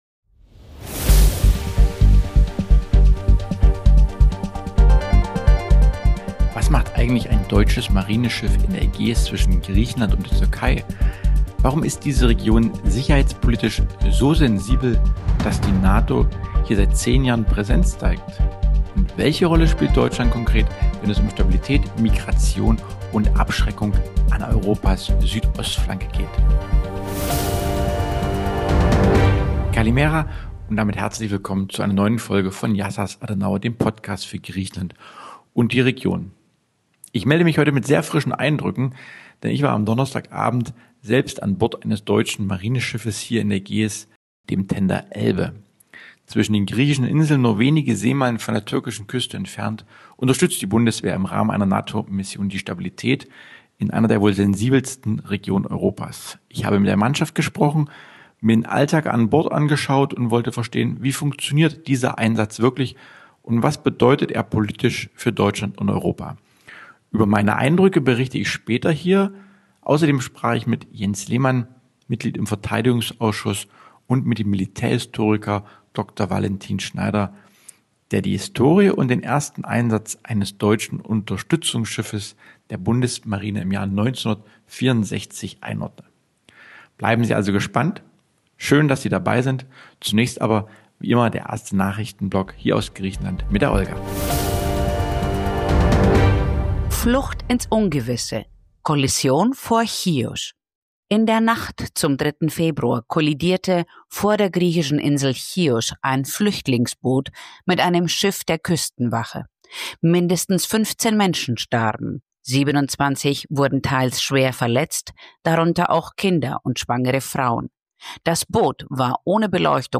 Beschreibung vor 2 Monaten Host Marian Wendt spricht über den Einsatz des deutschen Marineschiffs Tender „Elbe“ in der Ägäis. Im Mittelpunkt stehen Auftrag und Wirkung der NATO-Mission (seit 2016): Seeraumüberwachung, Beobachtung von Schleusernetzwerken, Lagebilder und Informationsaustausch zwischen Partnern.